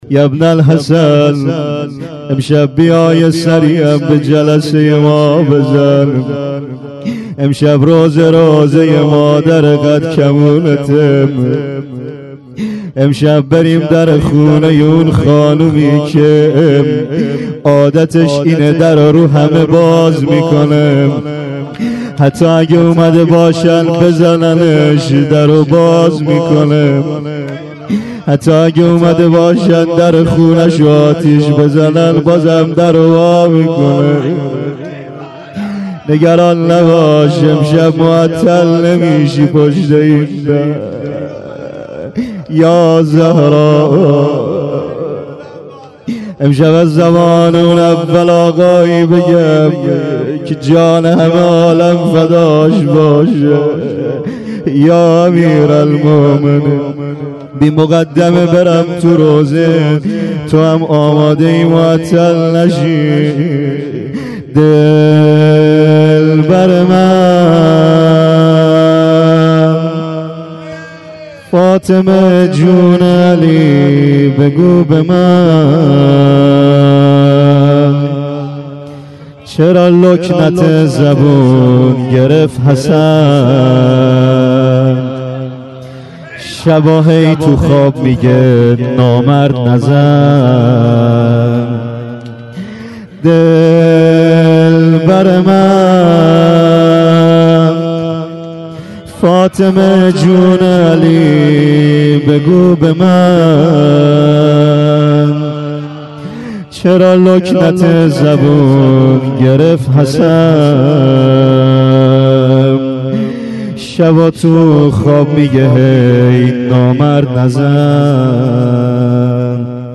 rozeh 93.06.21.mp3